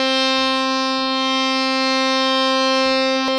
52-key12-harm-c4.wav